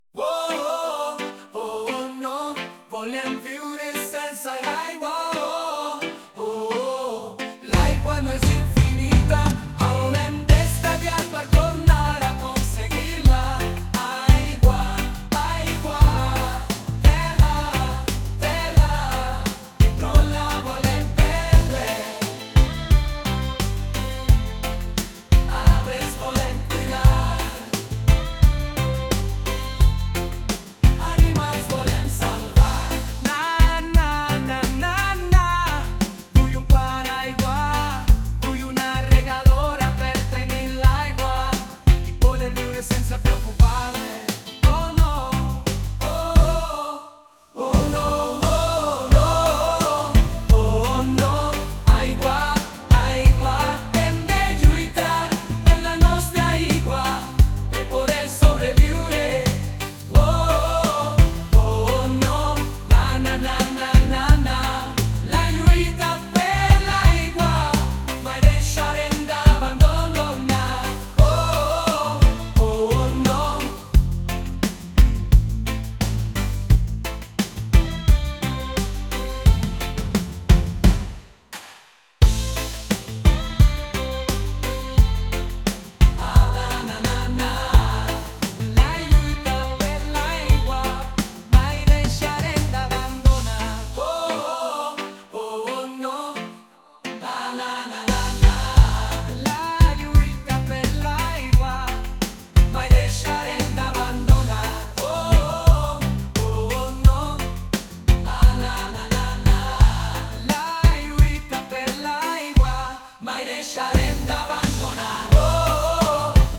Música generada per IA